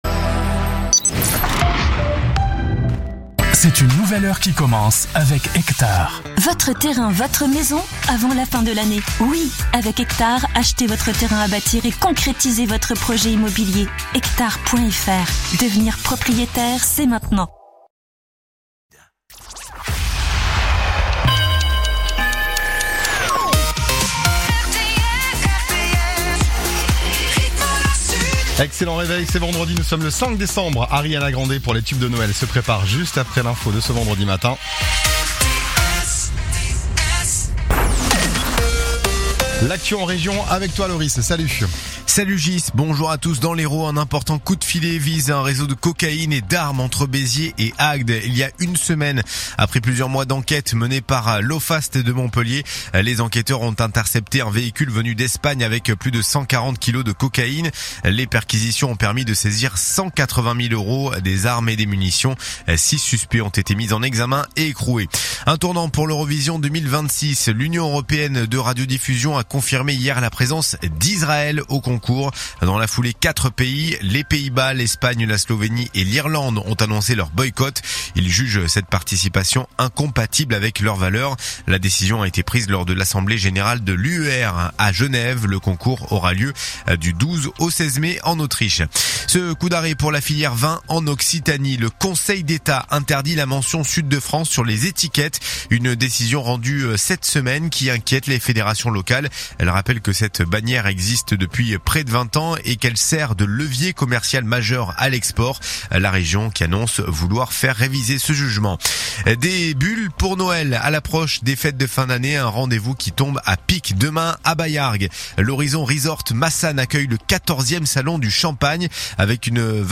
RTS : Réécoutez les flash infos et les différentes chroniques de votre radio⬦